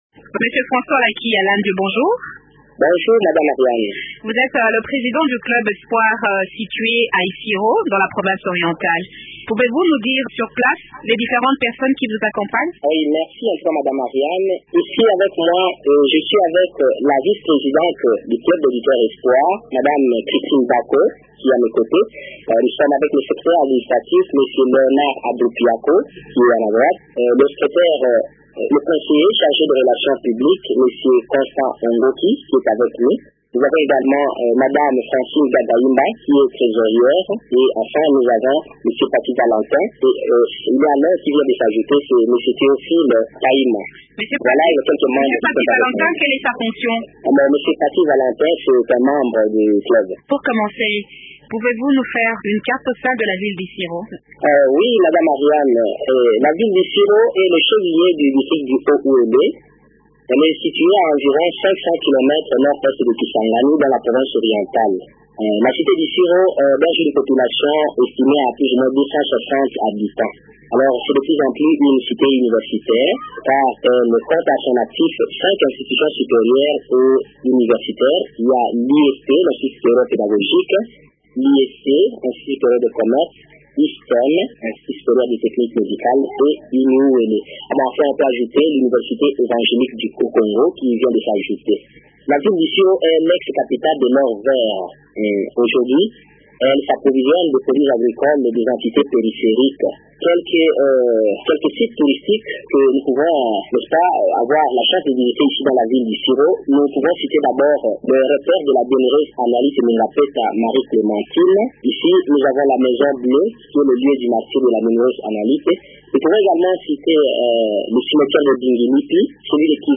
Le nouveau staff élu se fixe pour objectif d’accroître l’audience de radio Okapi dans cette ville de la Province orientale. Et cela, à travers la sensibilisation et l’écoute collective. Les animateurs du Caro Espoir s’entretiennent